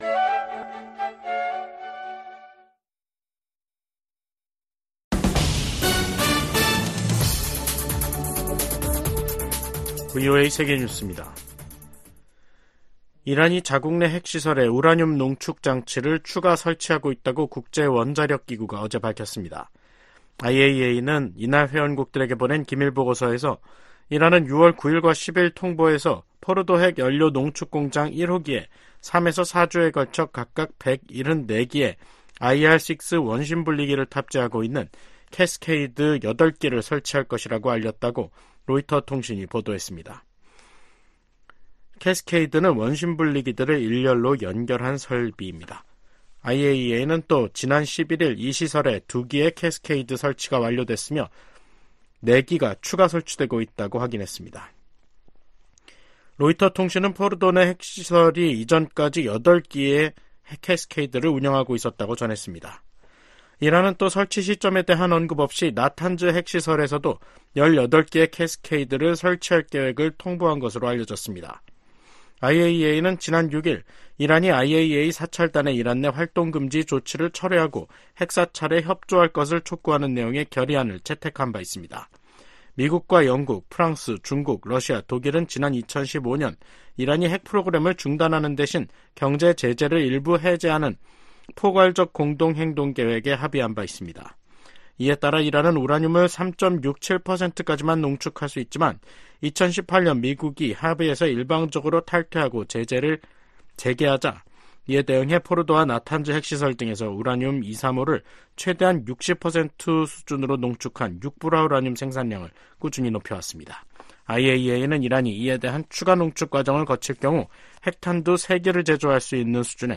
VOA 한국어 간판 뉴스 프로그램 '뉴스 투데이', 2024년 6월 14일 2부 방송입니다. 블라디미르 푸틴 러시아 대통령의 방북 임박설 속에 김일성 광장에 ‘무대’ 추정 대형 구조물이 등장했습니다. 미국의 전문가들은 푸틴 러시아 대통령의 방북이 동북아시아의 안보 지형을 바꿀 수도 있다고 진단했습니다. 미국 정부가 시행하는 대북 제재의 근거가 되는 ‘국가비상사태’가 또다시 1년 연장됐습니다.